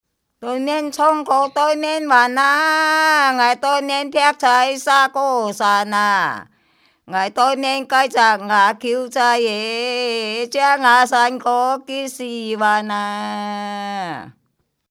區內一群長者聚在一起，輪流唱著塵封了的古老歌謠，越唱越開懷，不覺間勾勒出昔日農鄉的浮世繪。